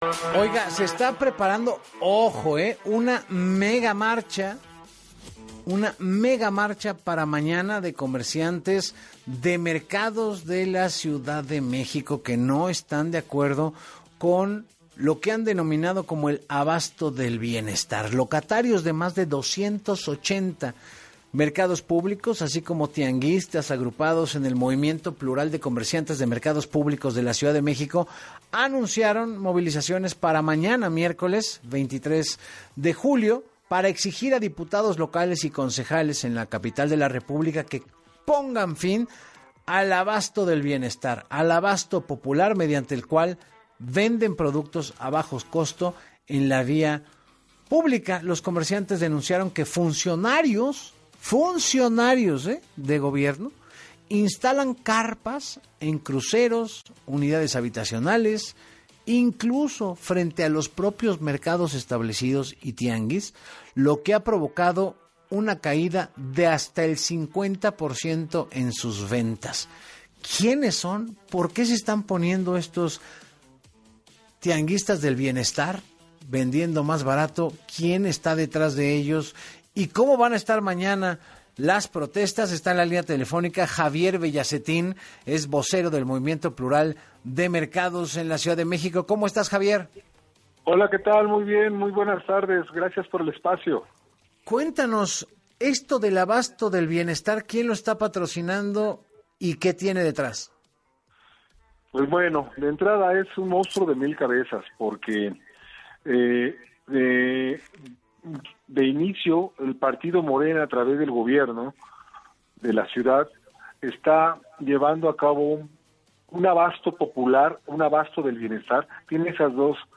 En entrevista con Enrique Hernández Alcázar